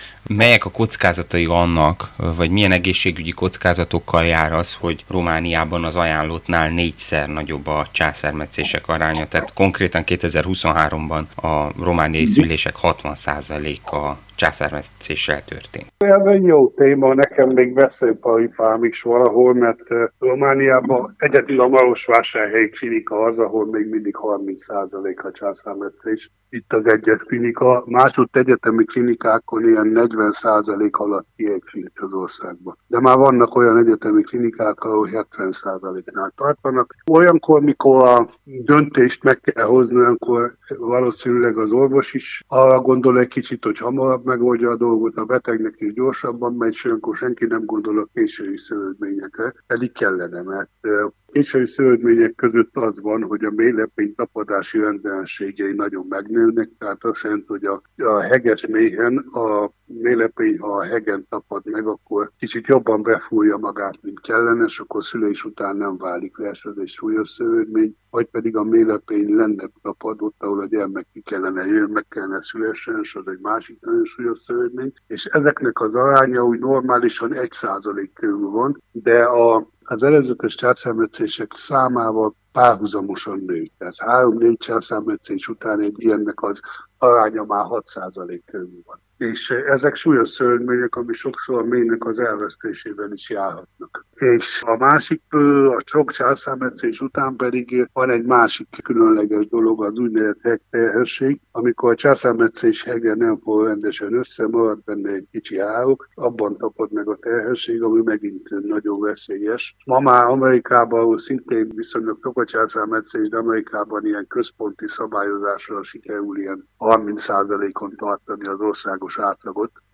interjúja.